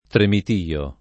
tremitio [ tremit & o ] s. m.